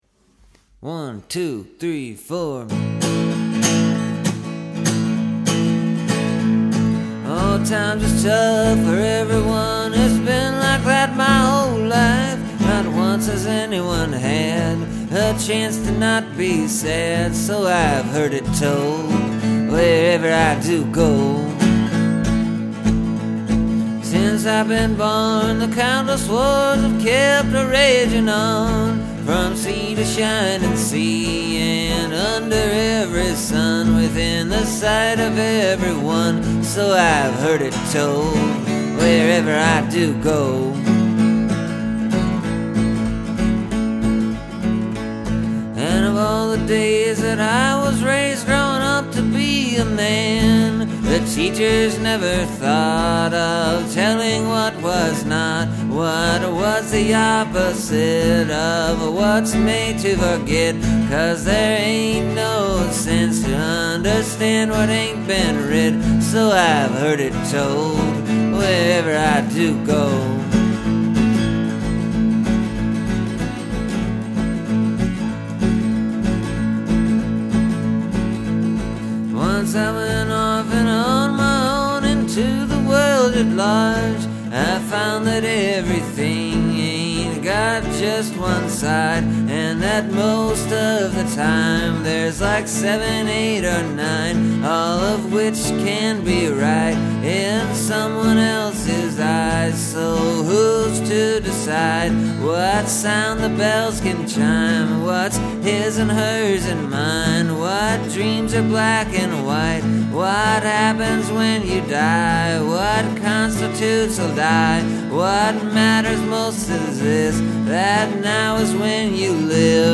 It’s a bit more stream-of-conscious, which I’m not sure of right now.
Maybe 30 minutes total to get all the words down and fumble through a couple different melodies and guitar arrangements.